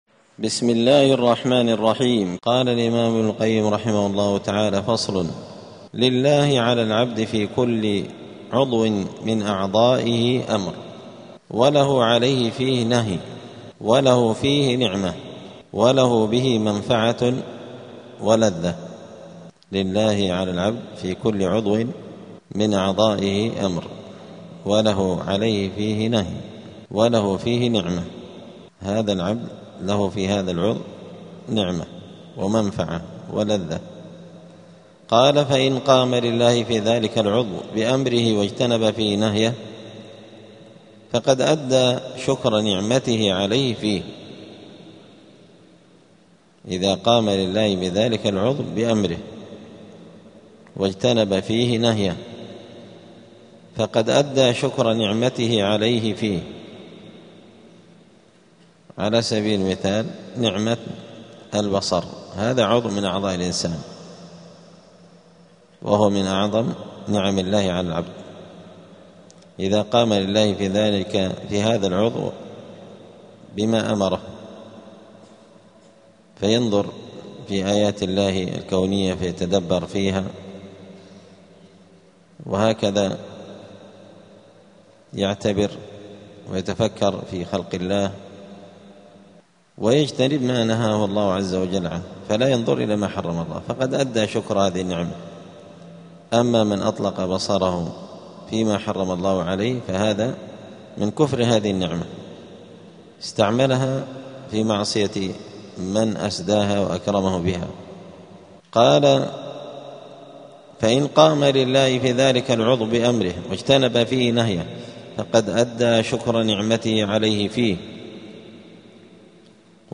الجمعة 23 جمادى الأولى 1447 هــــ | الدروس، دروس الآداب، كتاب الفوائد للإمام ابن القيم رحمه الله | شارك بتعليقك | 5 المشاهدات
دار الحديث السلفية بمسجد الفرقان قشن المهرة اليمن